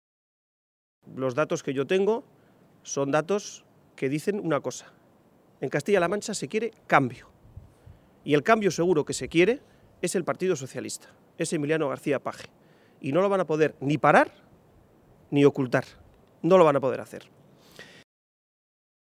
García-Page se pronunciaba de esta manera esta mañana, en Toledo, en una comparecencia ante los medios de comunicación durante la reunión que ha dirigido junto al secretario de organización federal del PSOE, César Luena.